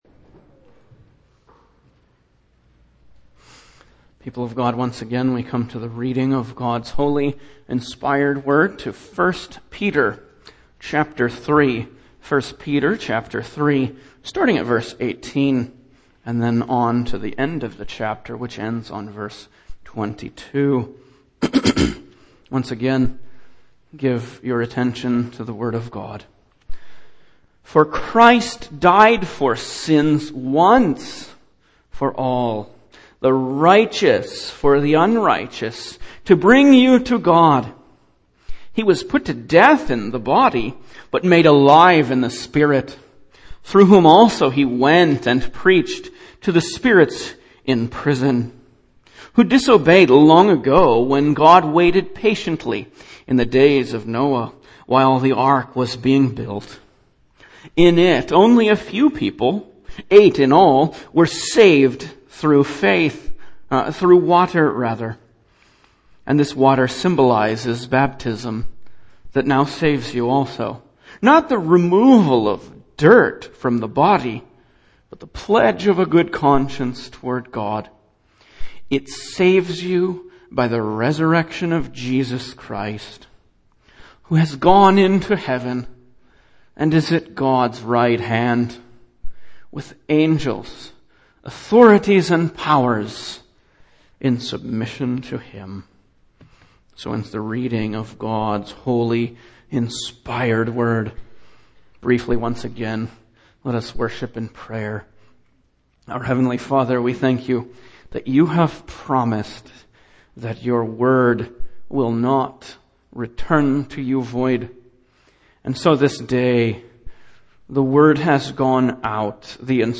Single Sermons Passage: 1 Peter 3:18-22 Service Type: Morning